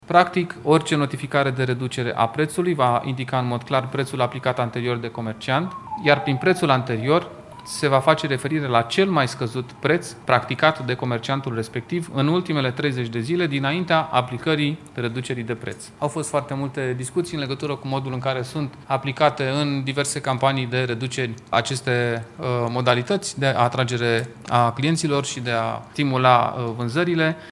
Purtătorul de cuvânt al Guvernului, Dan Cărbunaru: Consumatorii vor fi mai bine protejați în cadrul campaniilor de marketing și vânzări